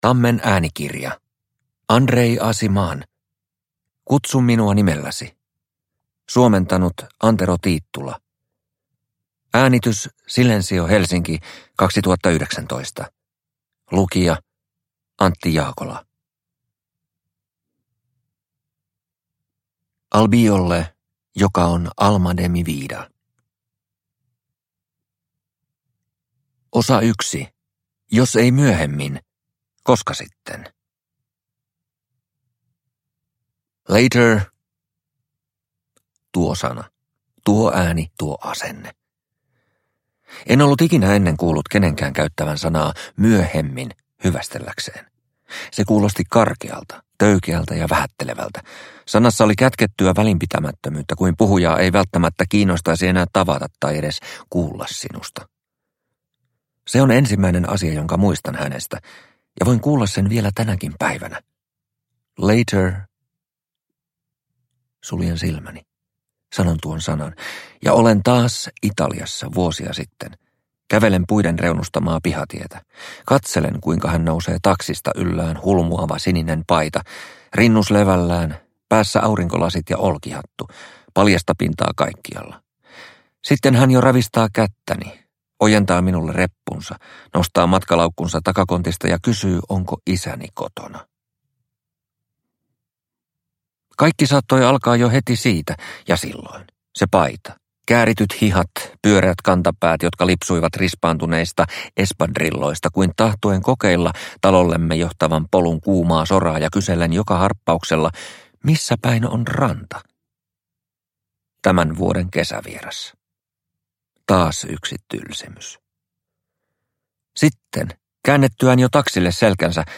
Kutsu minua nimelläsi – Ljudbok – Laddas ner